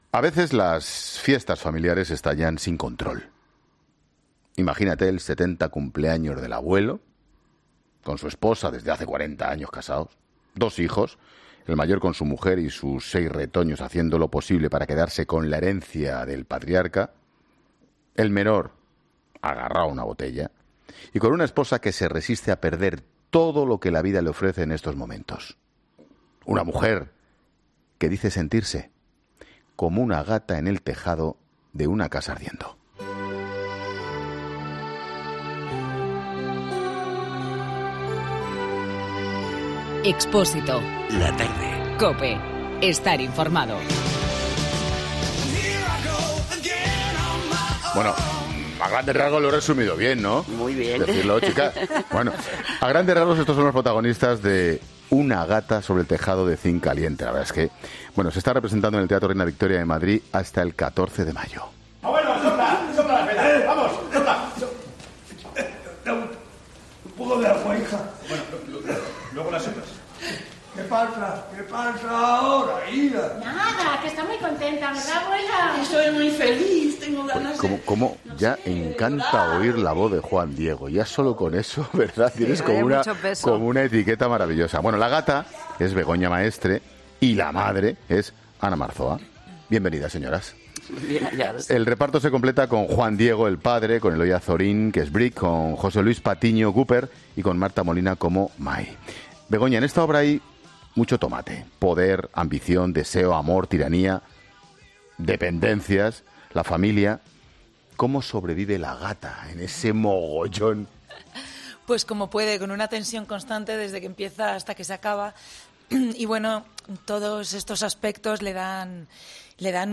Ambas actrices, que han visitado el estudio de 'La Tarde' protagonizan la obra junto a Juan Diego y Eloy Azorín.